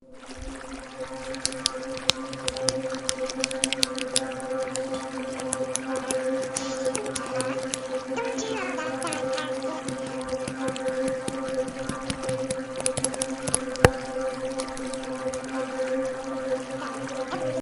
Free SFX sound effect: Rift Elves At Work.